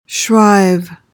PRONUNCIATION: (shryv) MEANING: verb tr.:1.